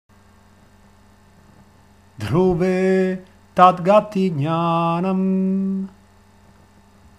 Vibhuti Padah canto vedico